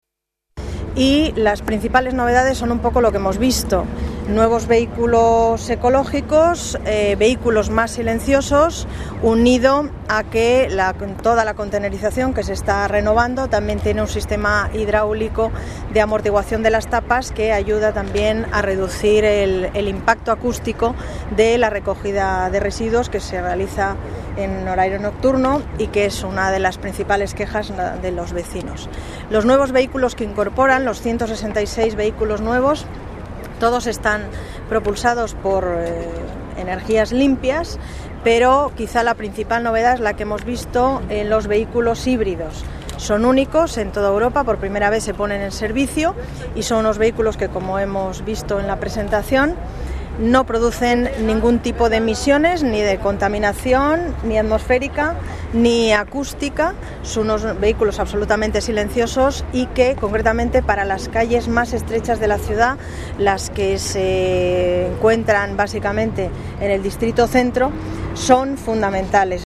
Nueva ventana:Paz González destaca las ventajas de estos nuevos vehículos, menos contaminantes y también menos ruidosos